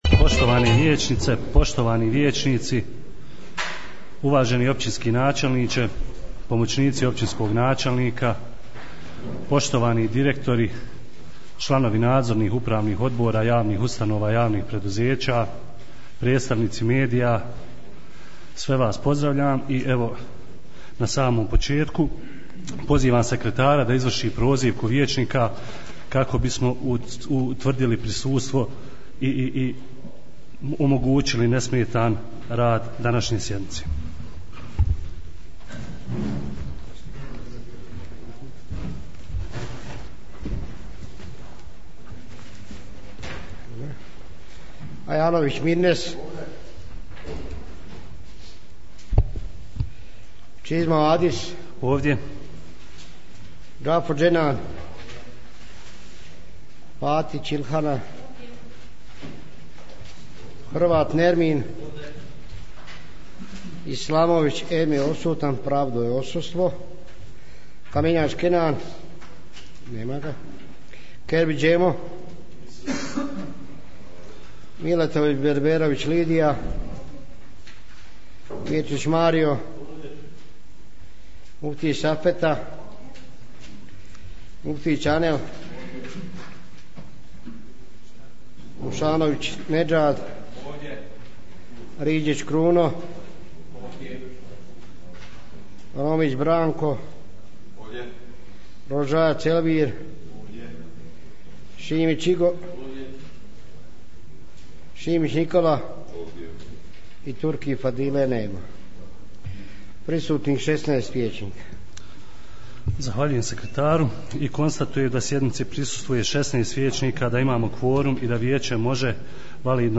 27. sjednica Općinskog vijeća Vareš
30.'5.2019. godine održana je 27. sjednica Općinskog vijeća Vareš. poslušajte tonski snimak.....